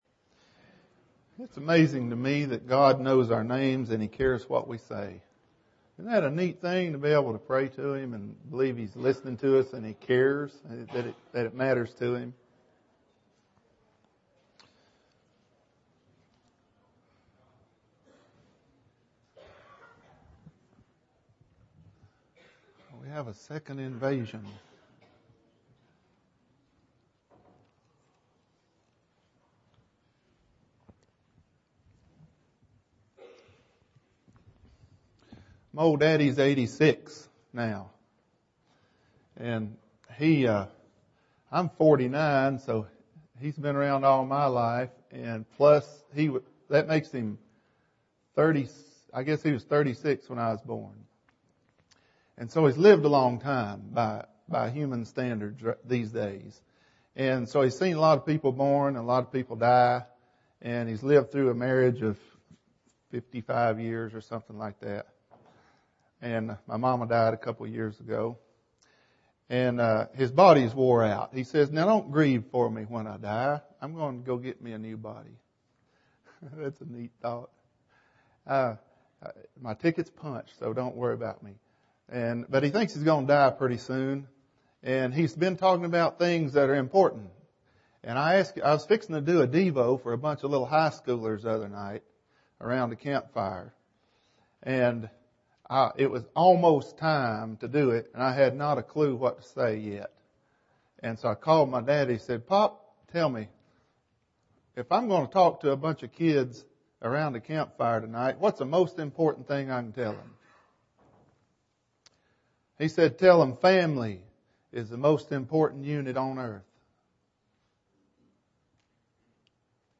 Introduction (1 of 12) – Bible Lesson Recording